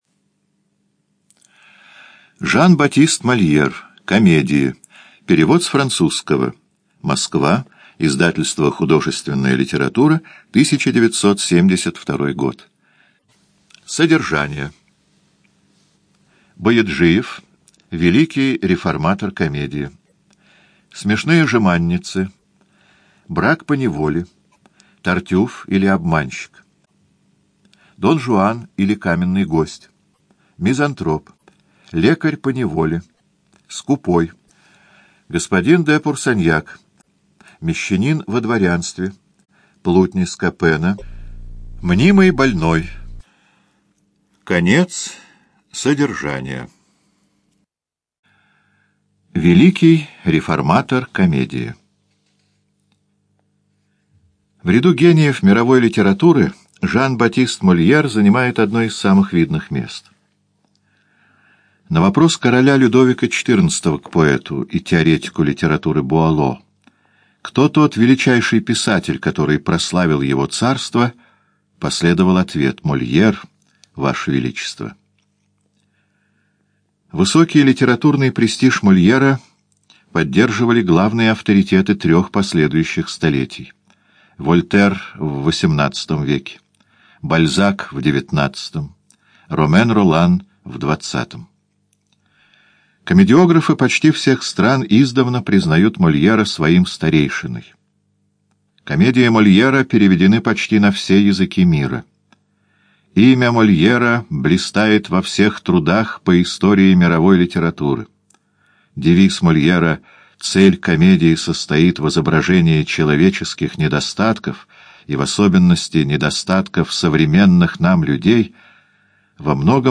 ЖанрДраматургия
Студия звукозаписиЛогосвос